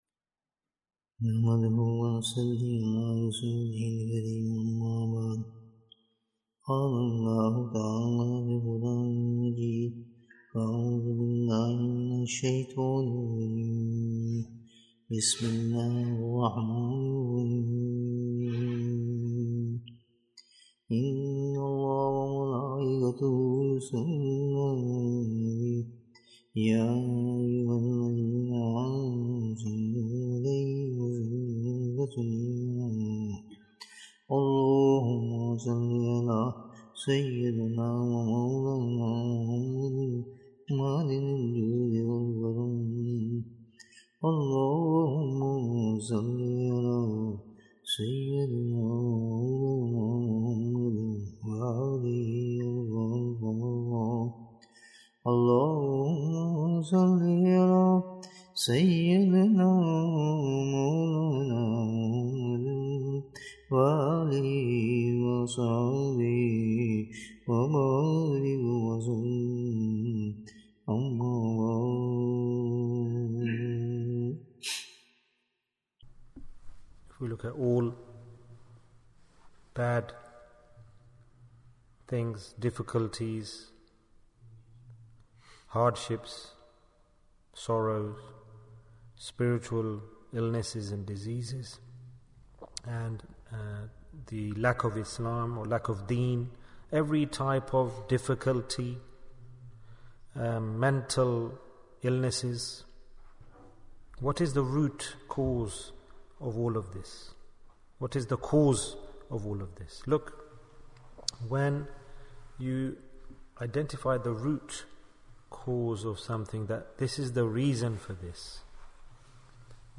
The Reason & Cure for Disobedience Bayan, 76 minutes1st December, 2022